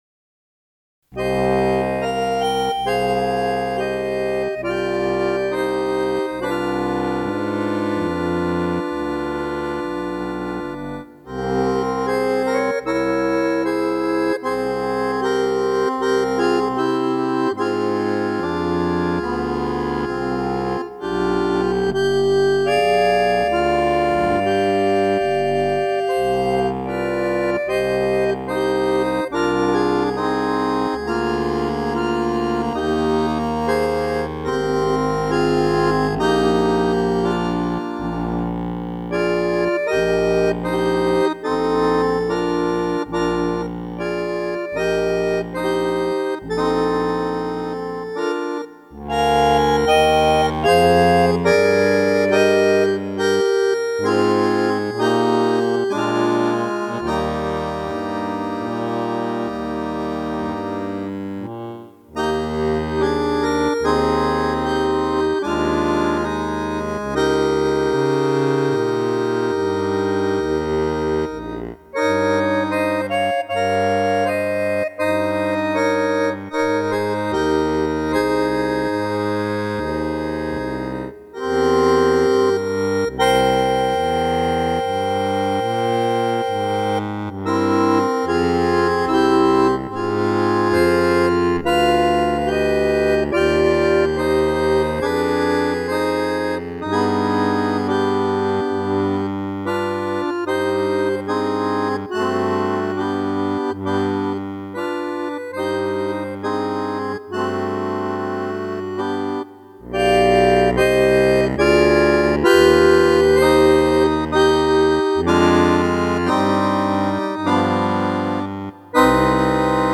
Naturbelassen aus dem Klavierauszug, passt in die Zeit ...